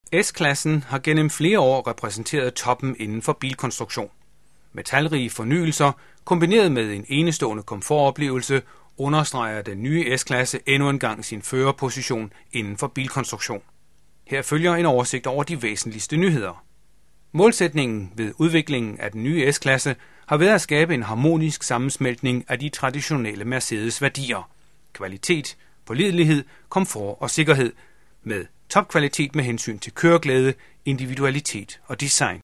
deutscher Profi-Sprecher.
Sprechprobe: Werbung (Muttersprache):